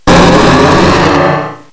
cry_not_mega_beedrill.aif